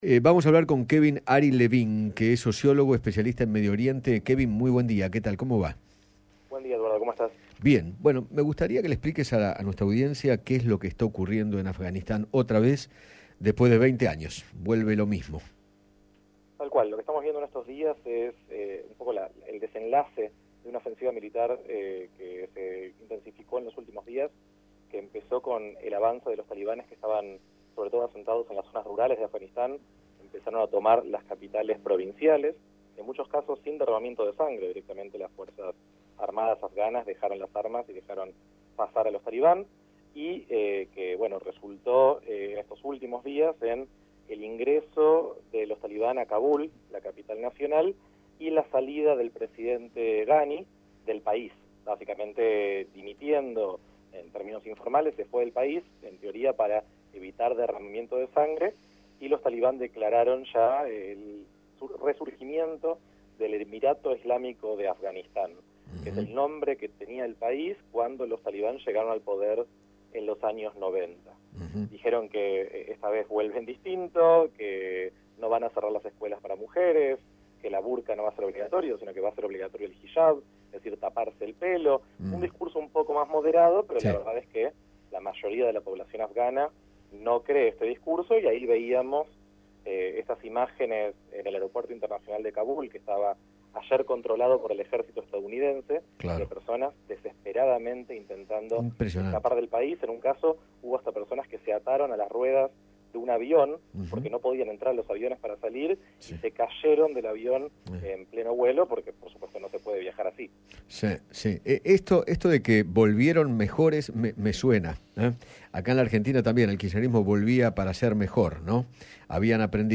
sociólogo especialista en temas de Medio Oriente